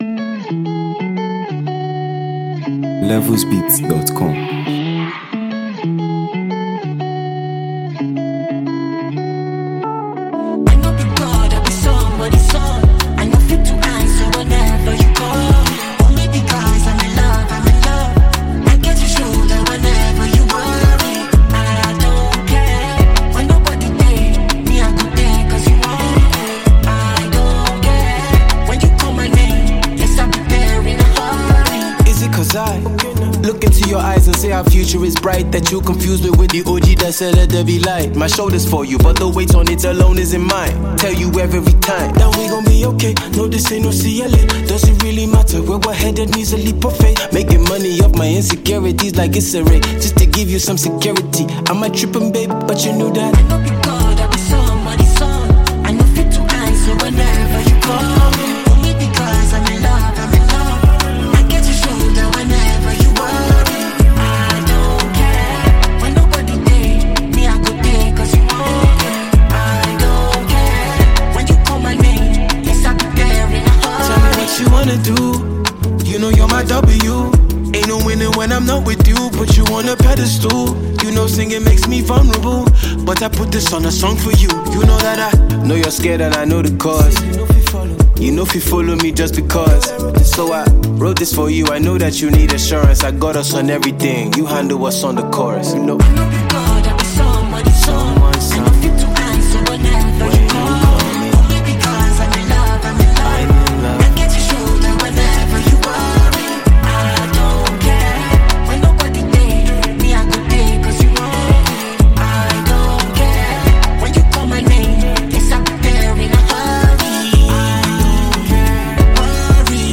Blending conscious rap with smooth production